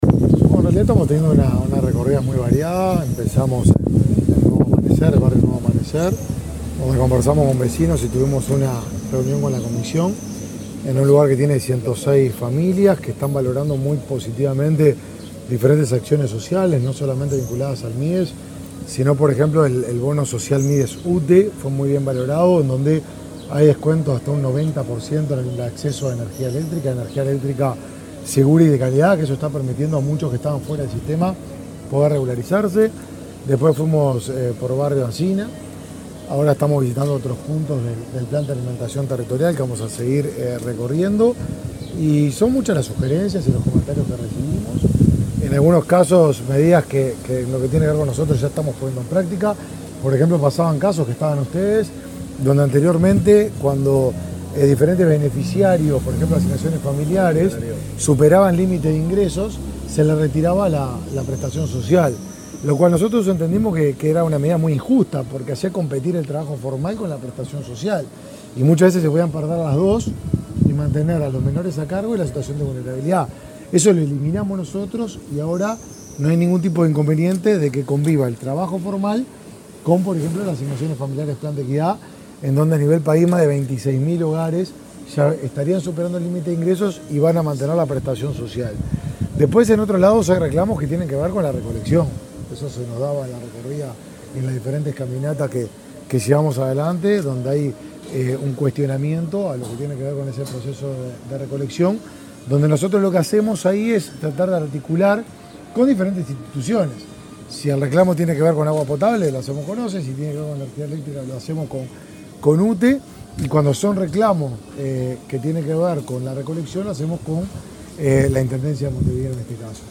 Declaraciones del ministro de Desarrollo Social, Martín Lema
Declaraciones del ministro de Desarrollo Social, Martín Lema 04/01/2024 Compartir Facebook X Copiar enlace WhatsApp LinkedIn El ministro de Desarrollo Social, Martín Lema, dialogó con la prensa durante una recorrida que realizó, este miércoles 3, por diferentes zonas de Montevideo.